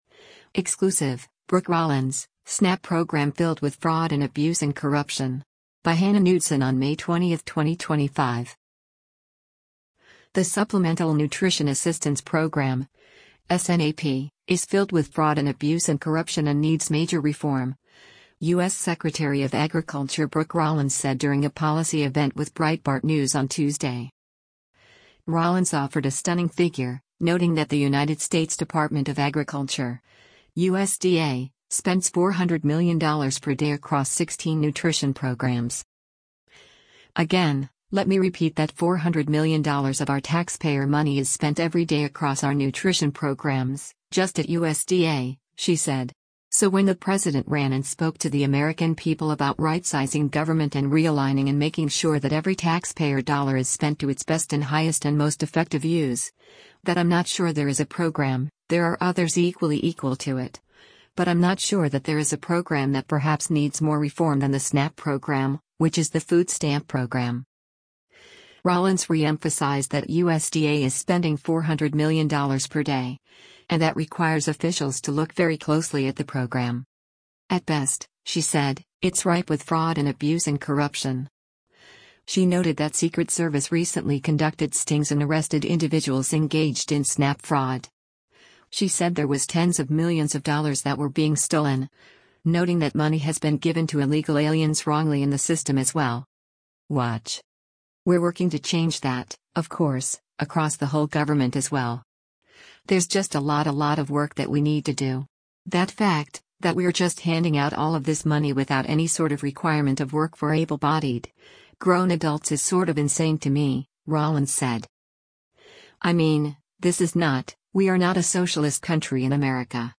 The Supplemental Nutrition Assistance Program (SNAP) is filled with “fraud and abuse and corruption” and needs major reform, U.S. Secretary of Agriculture Brooke Rollins said during a policy event with Breitbart News on Tuesday.